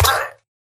Sound / Minecraft / mob / villager / death.ogg
death.ogg